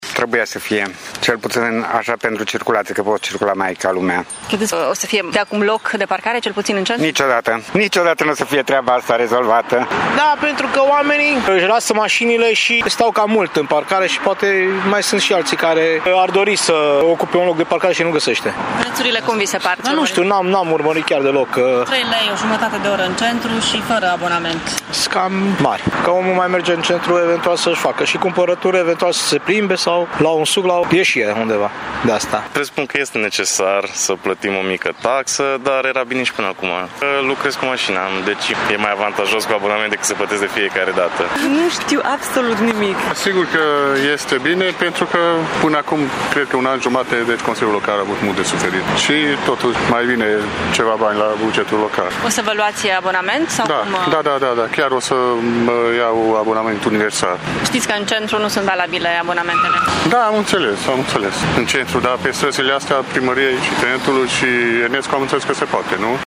Târgumureșenii nu știu încă prea bine regulamentele de parcare în oraș, în schimb, consideră că era necesară reintroducerea taxei de parcare: